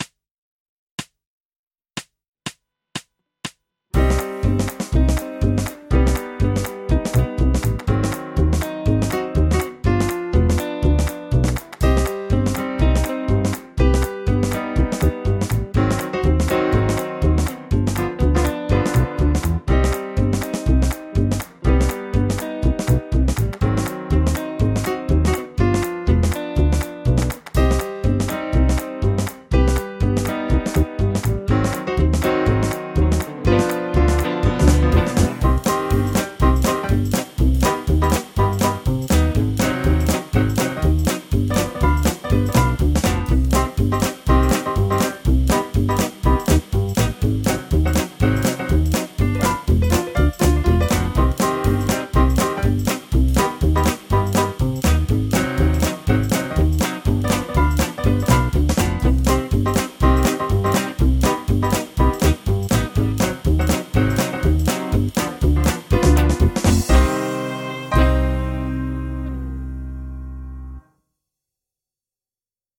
ハンガリアン・マイナー・スケール ギタースケールハンドブック -島村楽器